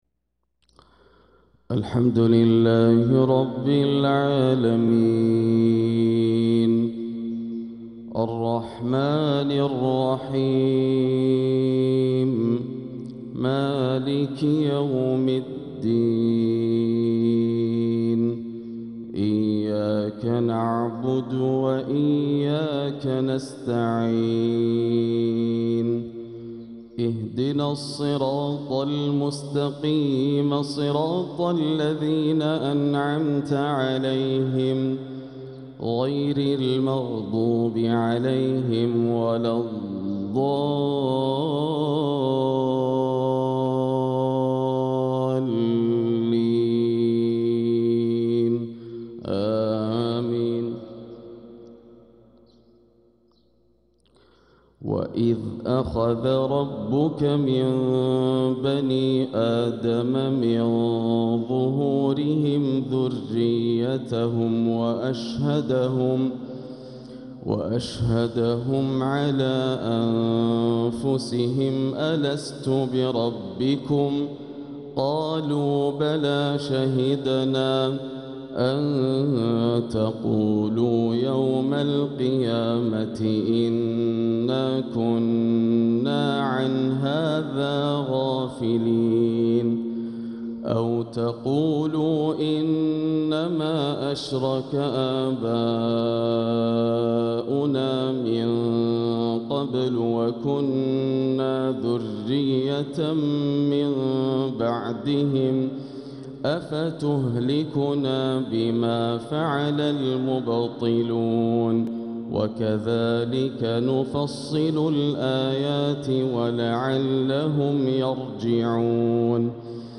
فجر الاثنين 4-8-1446هـ | من سورة الأعراف 172-188 | Fajr prayer from Surat al-A`raf 3-2-2025 > 1446 🕋 > الفروض - تلاوات الحرمين